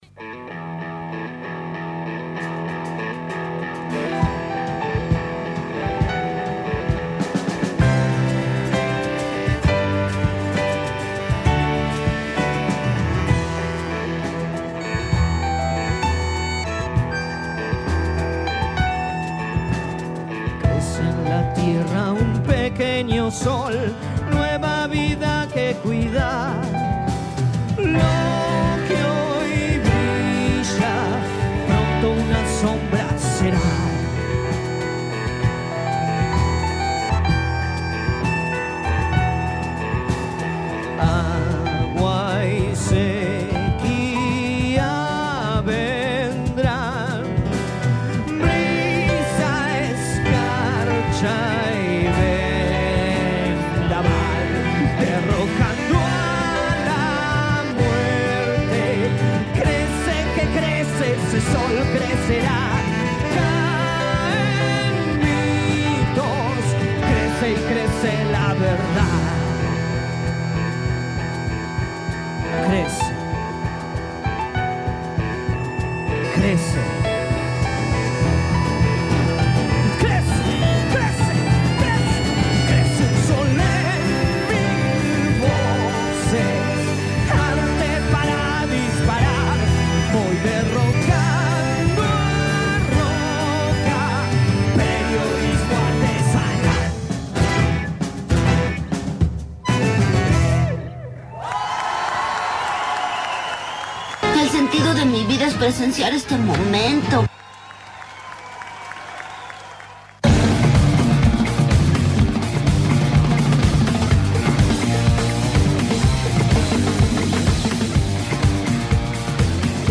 Entrevistas principales y en simultáneo